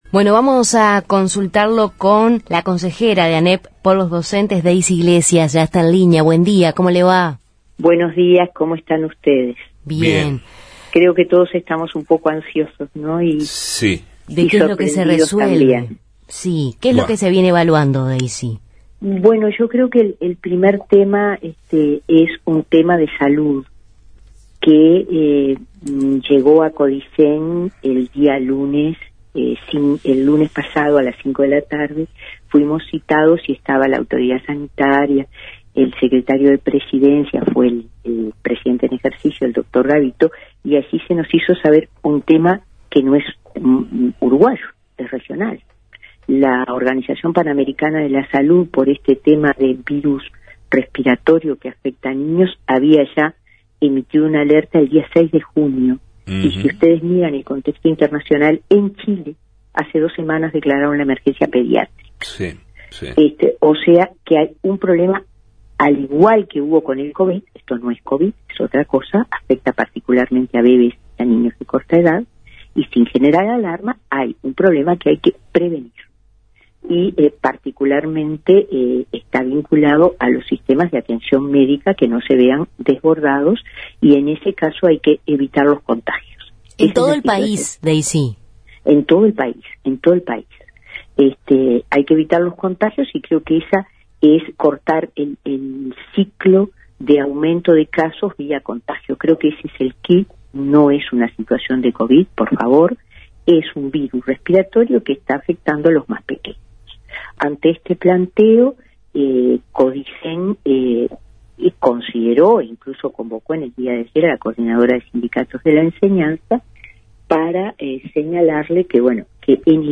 En Justos y pecadores entrevistamos a Daysi Iglesias, consejera de ANEP por los docentes, sobre el adelanto de vacaciones de julio por infecciones respiratorias en niños